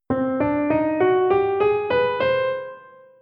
Paradiddle Kapitel 1 → Ungarische (Zigeuner-)Molltonleiter - Musikschule »allégro«
Die Zigeuner-Molltonleiter basiert im Grunde auf dem harmonischen Moll.
Sehr auffällig sind nun 4., 5. und 6. Stufe, denn hier liegen zwei Halbtonschritte direkt nebeneinander: f#-g-a♭.
TonleiternMollUngarischZigeuner.mp3